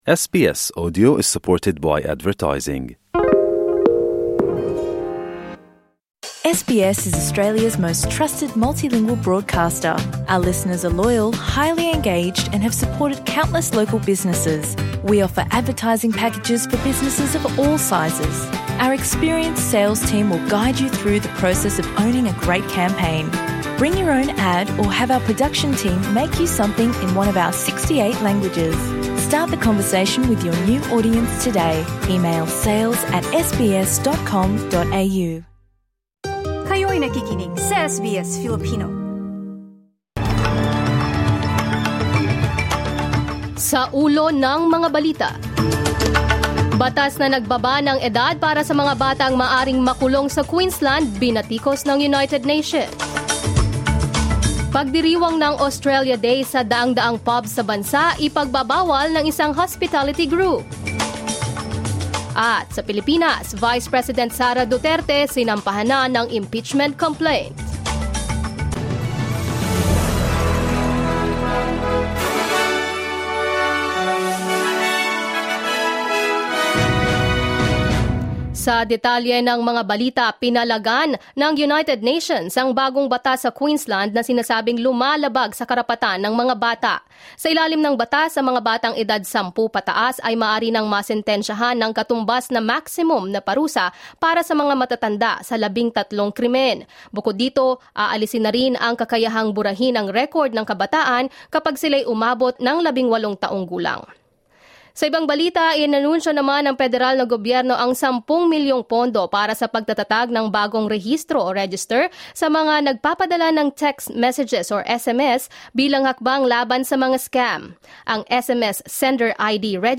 SBS News in Filipino, Tuesday 3 December 2024